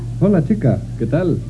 bokstaven H utalas aldrig i spanskan.